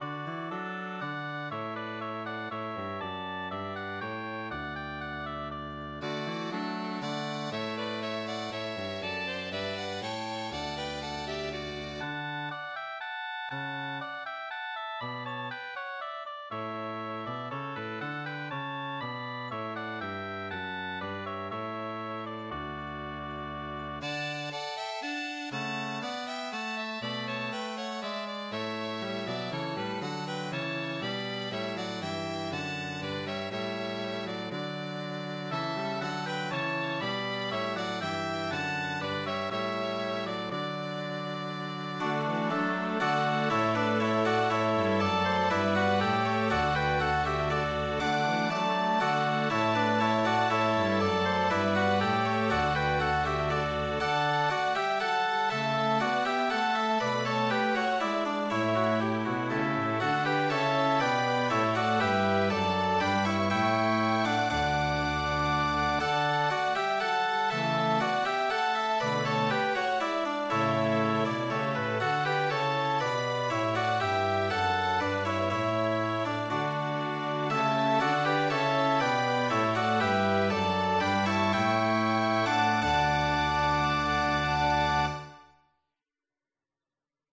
(4) Come, come ye Sons of Art, alt